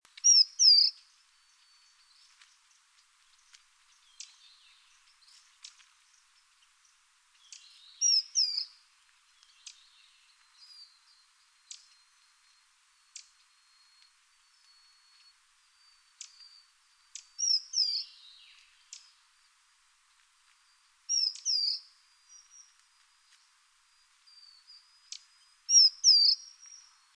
29-6檢查哨2012mar26灰頭花翼2.mp3
紋喉雀鶥 Alcippe cinereiceps formosana
南投縣 信義鄉 塔塔加
錄音環境 草叢
鳥叫
Sennheiser 型號 ME 67